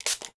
SPRAY_Manual_RR3_mono.wav